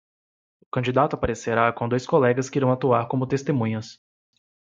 Read more to act to operate, function to influence Frequency C2 Pronounced as (IPA) /a.tuˈa(ʁ)/ Etymology Borrowed from Medieval Latin āctuāre (“actuate”), from Latin āctus, perfect passive participle of agō (“do, act”).